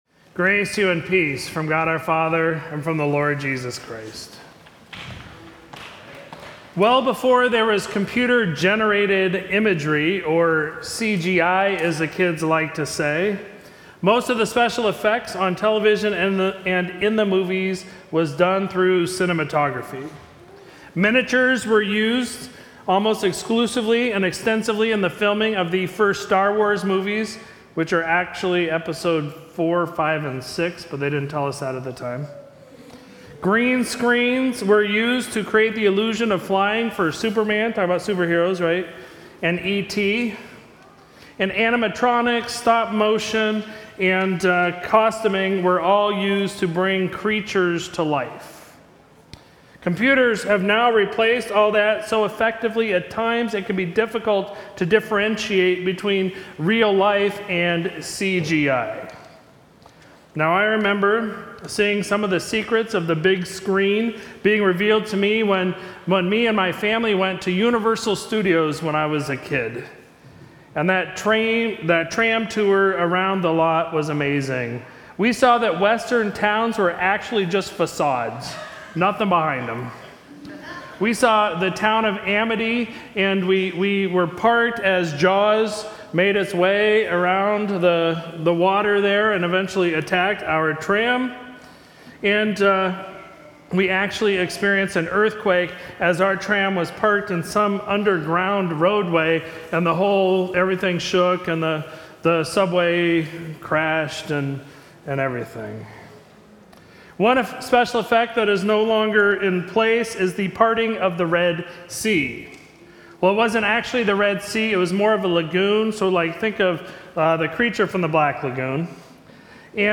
Sermon for Sunday, October 2, 2022